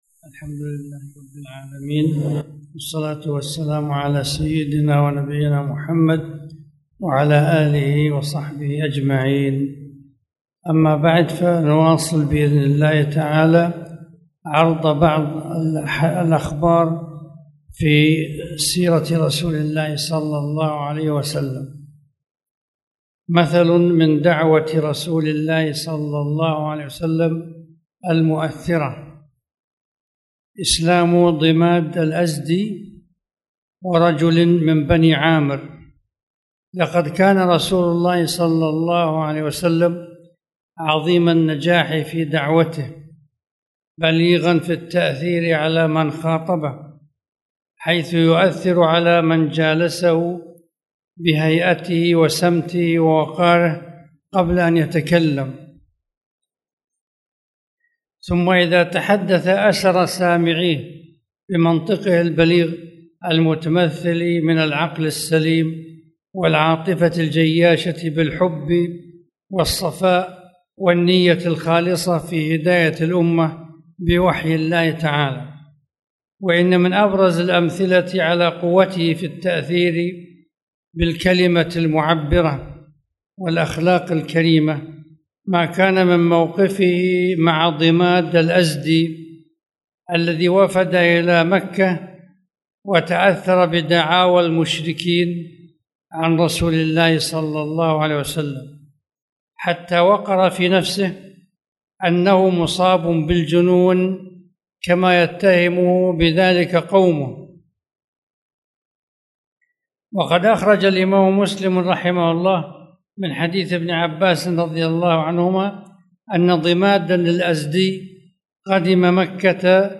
تاريخ النشر ٢٣ ربيع الثاني ١٤٣٨ هـ المكان: المسجد الحرام الشيخ